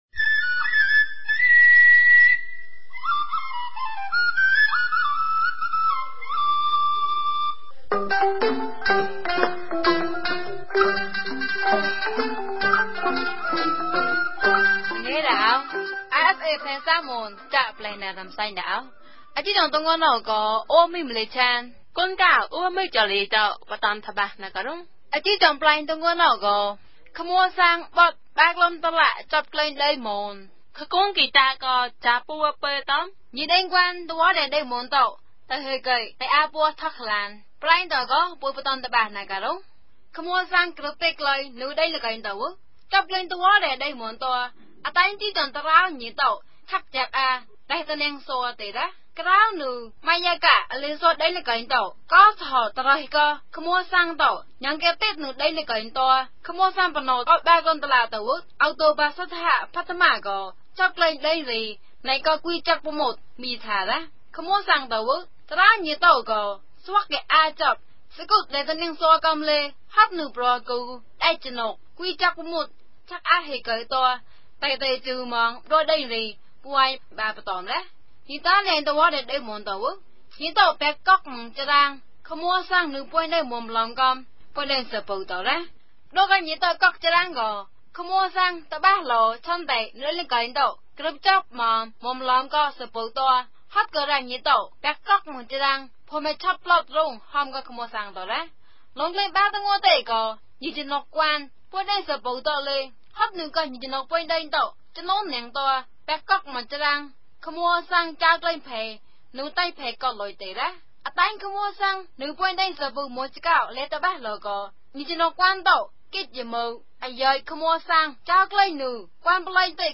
မြန်ဘာသာ အသံလြင့်အစီအစဉ်မဵား